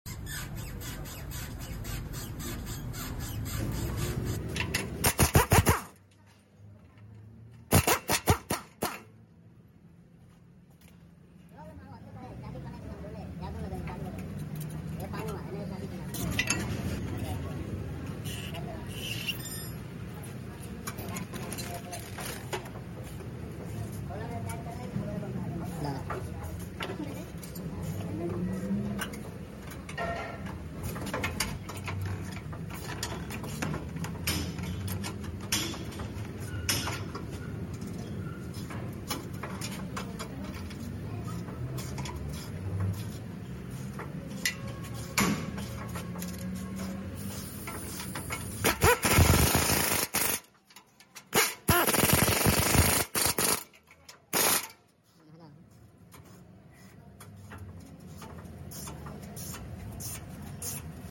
suspension stabilizar bush sound install sound effects free download